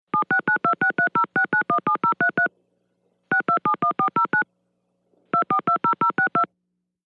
Futz|Touch Tone Dialing
Automatic touch tone telephone dialing 14, 7 & 7 digits